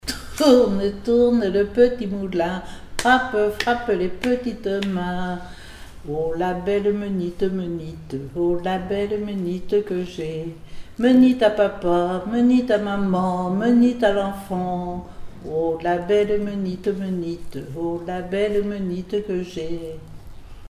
Île-d'Yeu (L')
formulette enfantine : amusette
Comptines et formulettes enfantines